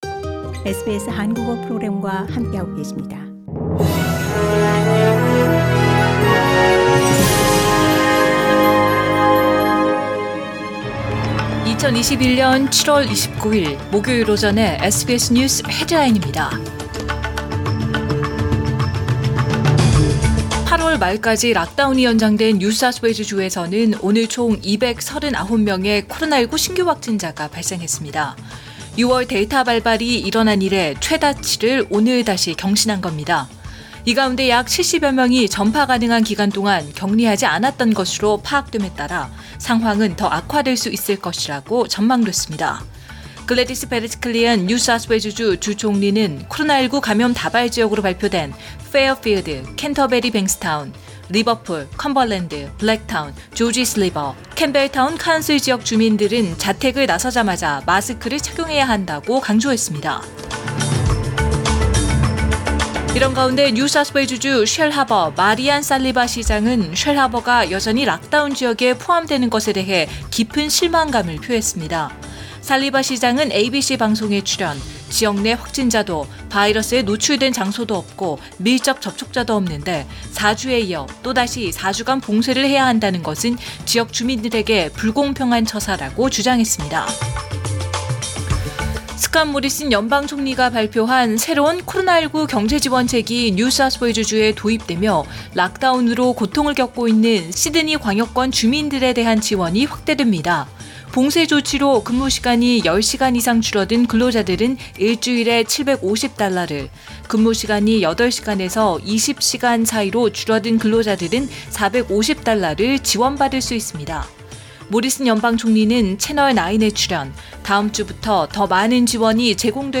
2021년 7월 29일 목요일 오전의 SBS 뉴스 헤드라인입니다.